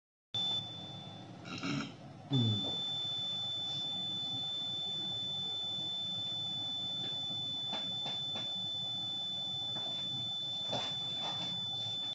LM4670: Output Noise without any Input
The sound is similar to a continuous hiss.